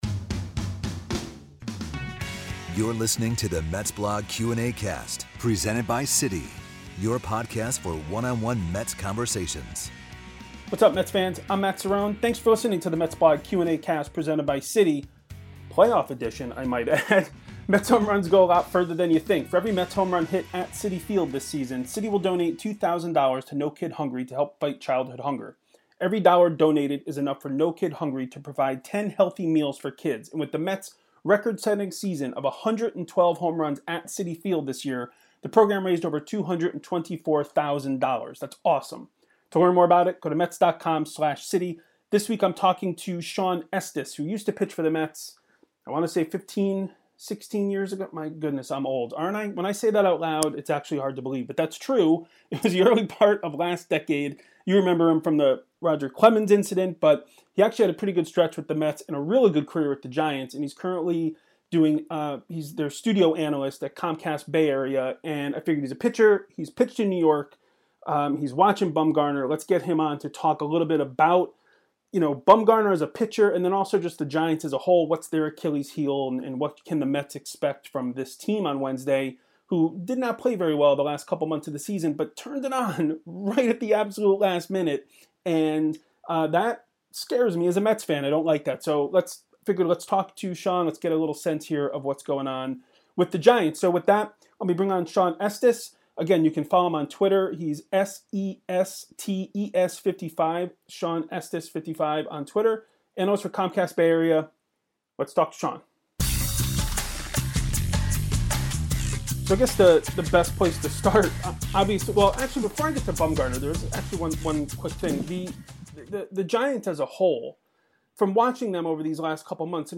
former Mets pitcher and current Giants broadcaster Shawn Estes, who does pre and post game analysis for Comcast SportsNet Bay Area